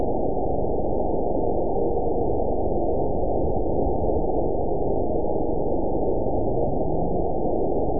event 912032 date 03/16/22 time 12:33:05 GMT (3 years, 1 month ago) score 8.34 location TSS-AB05 detected by nrw target species NRW annotations +NRW Spectrogram: Frequency (kHz) vs. Time (s) audio not available .wav